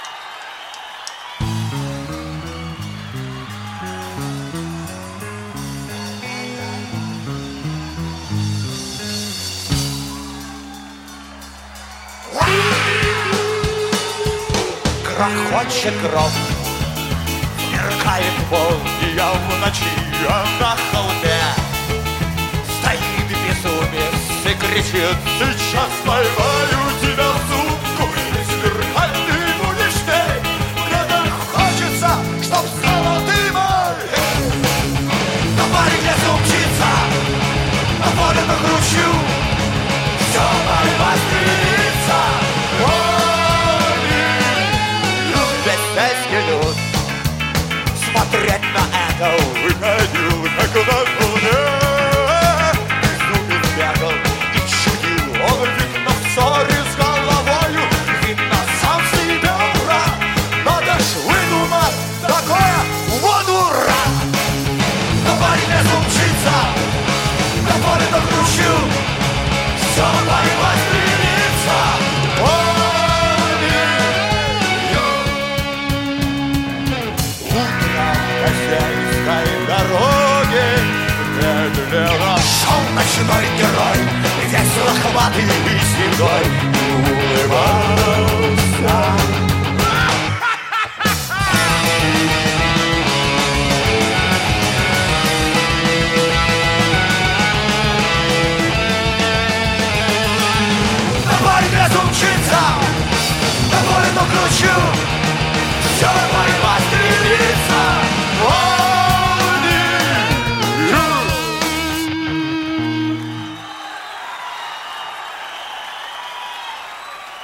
Метал
Жанр: Метал / Рок